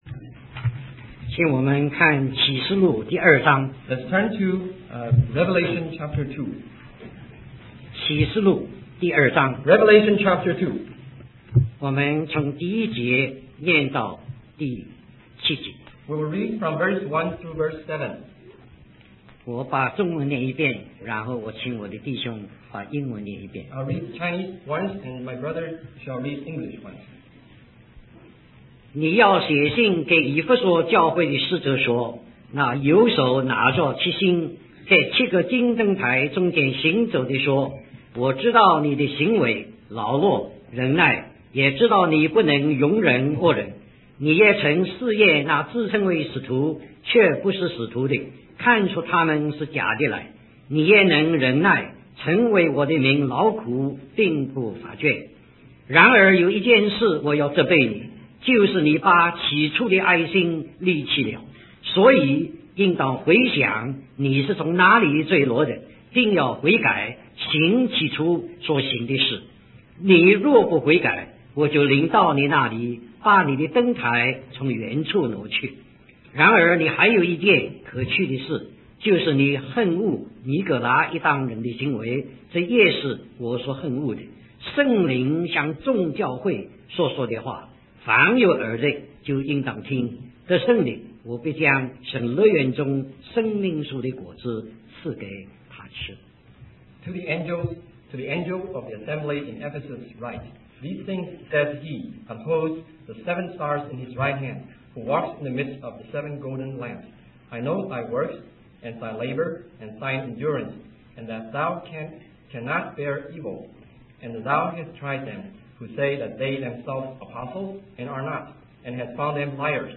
In this sermon, A.J. Gordon shares a dream he had while preparing a message for his church.